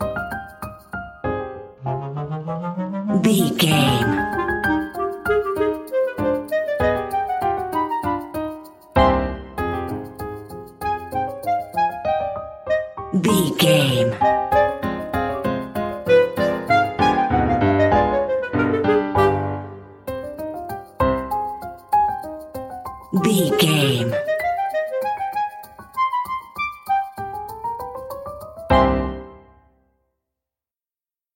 Aeolian/Minor
flute
oboe
strings
circus
goofy
comical
cheerful
perky
Light hearted
quirky